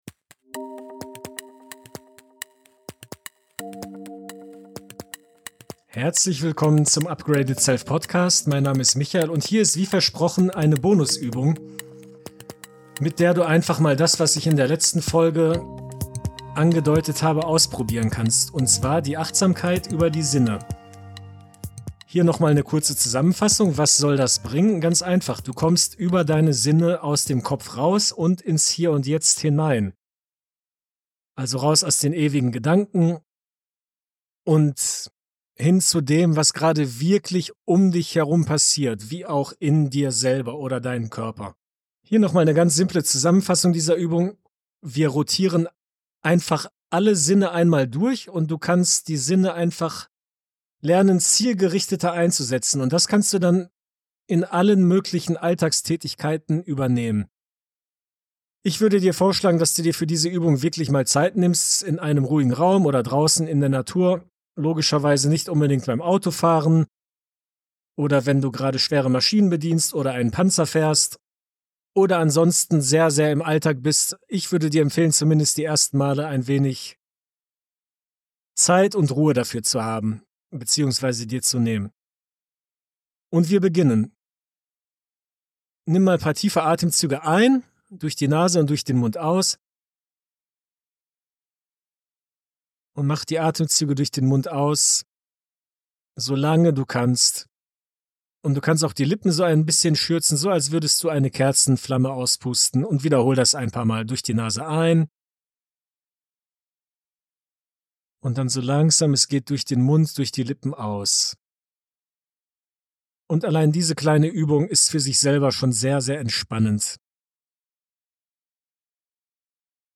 Eine angeleitete Bonusübung zum achtsamen Umgang mit den Sinnen, angelehnt an die letzte Folge.
In dieser geführten Meditation mit begleitenden Erklärungen leite ich dich durch eine achtsame Reise deiner Sinne – Sehen, Hören, Fühlen, Riechen und Schmecken.